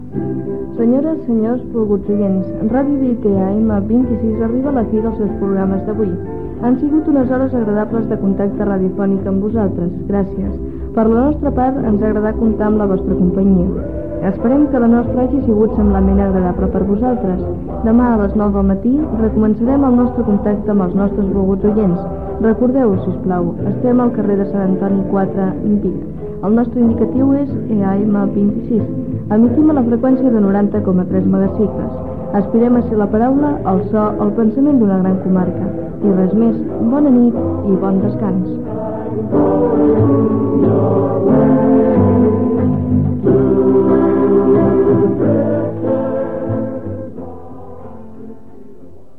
d70b8e6ea4d2e7a63e505137d4d85083bc415fb8.mp3 Títol Ràdio Vic Emissora Ràdio Vic Titularitat Privada local Descripció Tancament d'emissió.